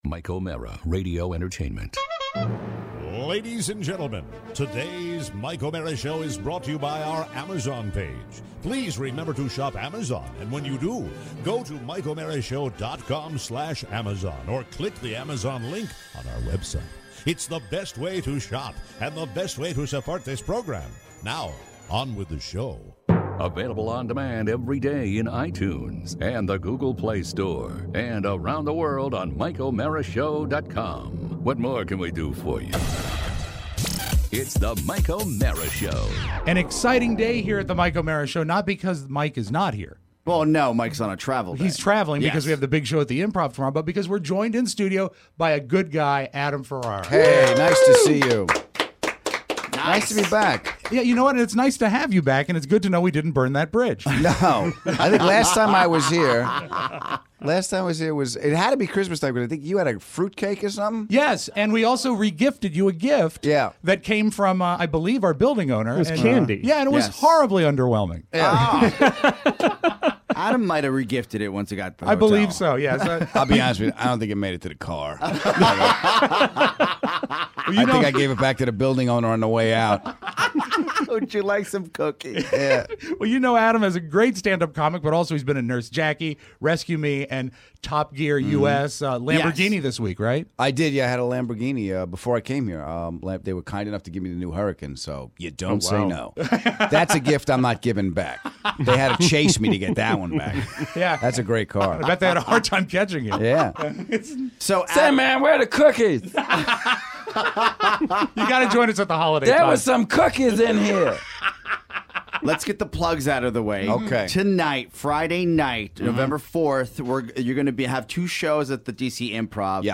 Good guy Adam Ferrara joins us in studio!